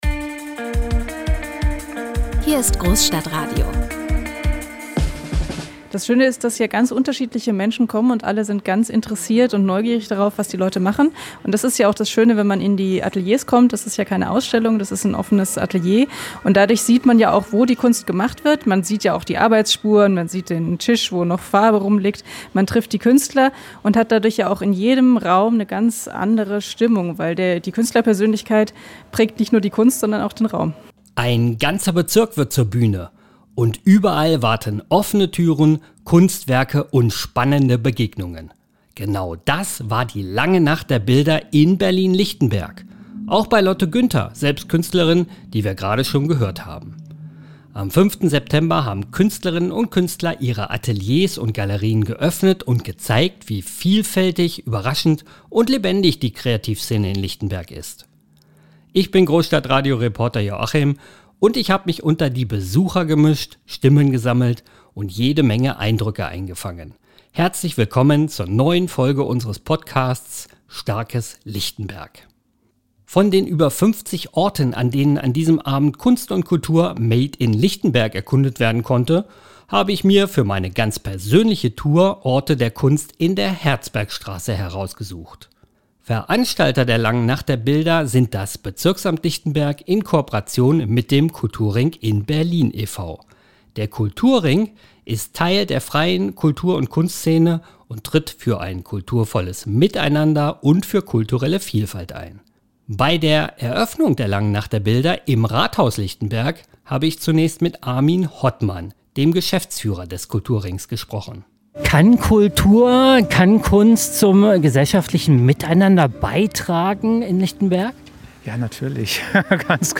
Genau das war die „Lange Nacht der Bilder“ in Berlin-Lichtenberg. Am 5. September haben Künstlerinnen und Künstler ihre Ateliers und Galerien geöffnet und gezeigt, wie vielfältig, überraschend und lebendig die Kreativszene in Lichtenberg ist.